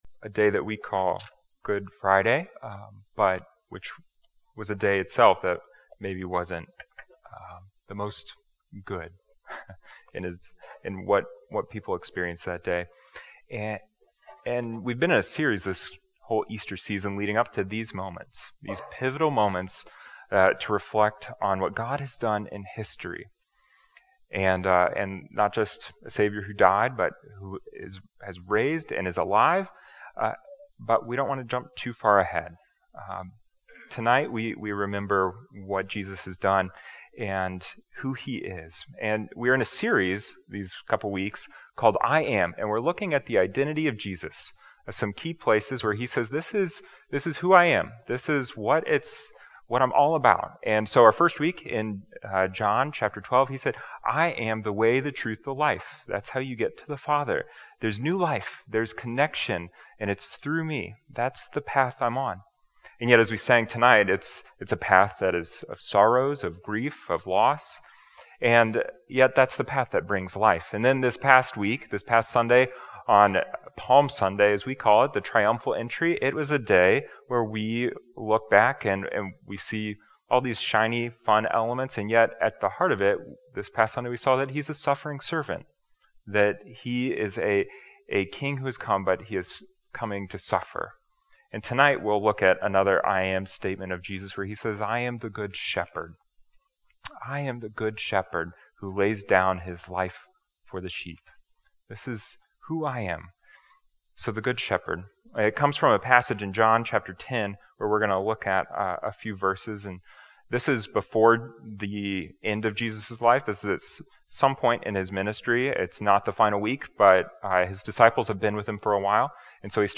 Join in with Good Friday service as we worship, pray and reflect on Jesus' claim of "I AM The Good Shepherd." Communion will be offered during service, so please grab the elements for that if you'd like to participate.